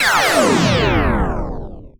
SCIFI_Down_04_mono.wav